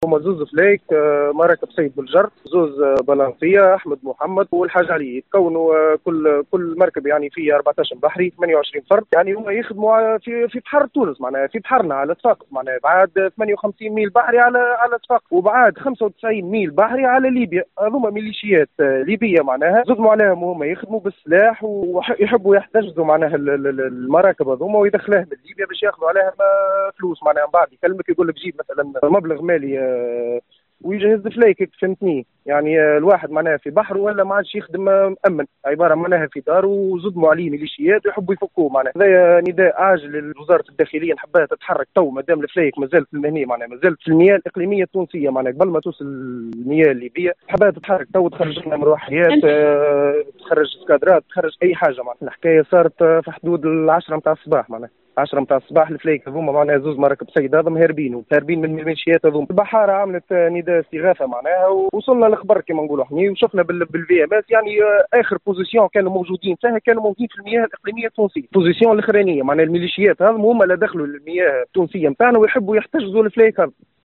اطلق ربان مركب صيد اليوم الاثنين على ال”ام اف ام” نداء استغاثة بعد ان تم احتجاز مركبي صيد على متنهما 28 بحارا اصيلي الشابة التابعة لولاية المهدية من قبل ميلشيات ليبية صباح اليوم في حدود الساعة العاشرة صباحا مفيدا ان البحارة قد تعرضوا إلى عملية قرصنة و احتجاز باستعمال السلاح وفق قوله.